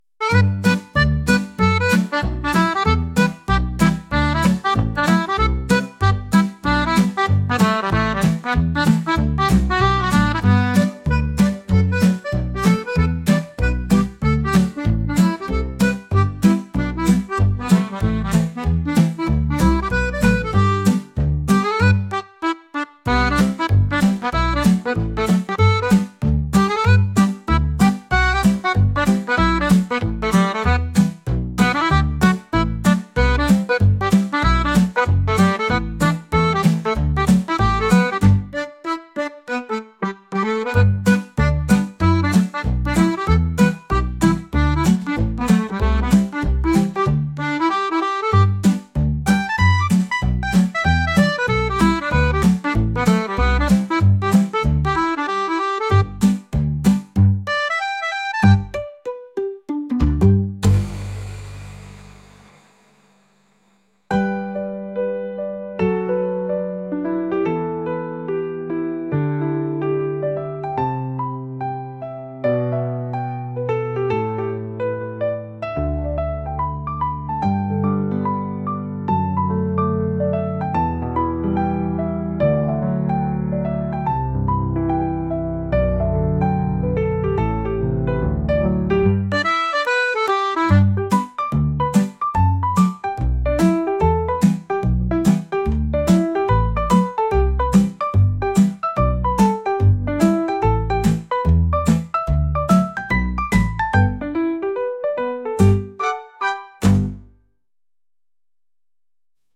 散歩している時にパンを食べるのを誘われるアコーディオンの音楽です。